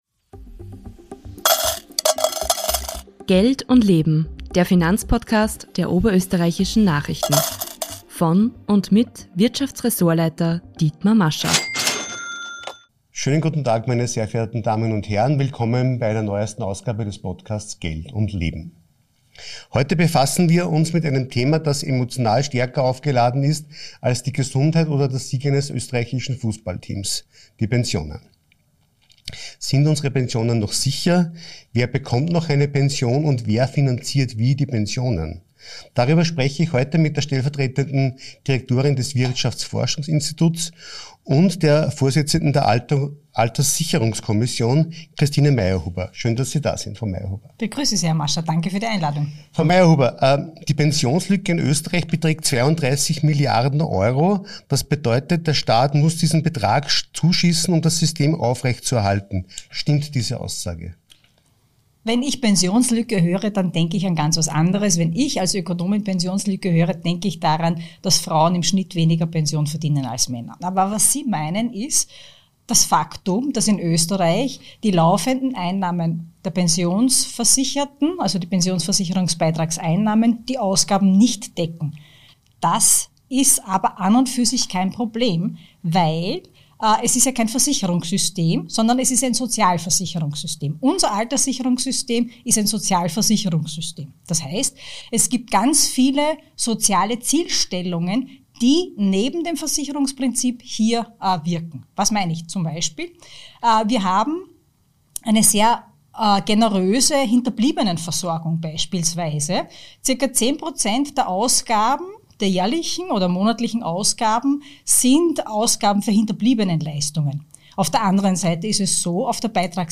Die Pensions- und Sozialexpertin erklärt im Gespräch, wie das Umlageverfahren und der Generationsvertrag zu verstehen sind und welche Leistungen Teil des Systems sind, die man überdenken müsste.